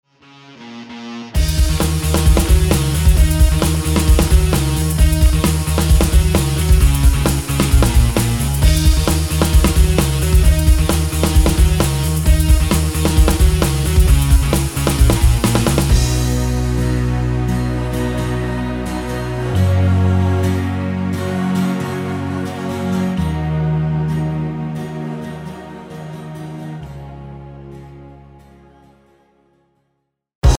KARAOKE/FORMÁT:
Žánr: Pop
BPM: 132
Key: D#m